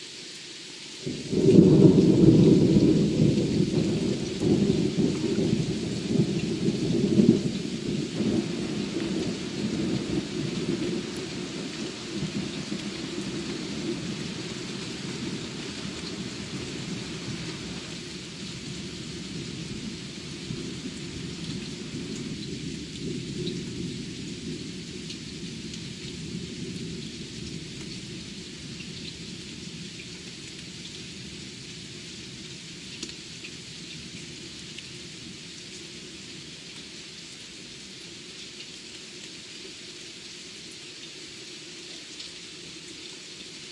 2017年7月23日晚的雷雨 " 遥远的雷声2 2017年7月23日
描述：2017年7月23日（2:30 AM）雷暴期间，一阵遥远的雷声从我家前面的倾盆大雨中听到 录制于2017年7月23日至7月24日夜间，我的JVC GZR415BE摄像机内置麦克风，位于法国GrandEst的Alutace，HautRhin村庄，因为多细胞雷暴袭击了该镇。
格式：立体声.wav文件，44.1khz，16位。